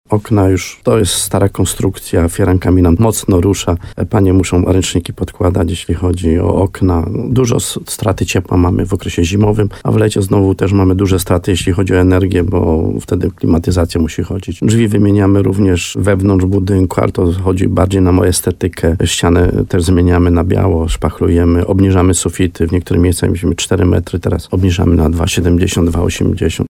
– Chodzi szczególnie o wymianę okien, które są nieszczelne i niefunkcjonalne, ale też dachu – mówił wójt gminy Chełmiec Stanisław Kuzak w programie Słowo za Słowo na antenie RDN Nowy Sącz.